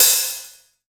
Brush Hat Open.wav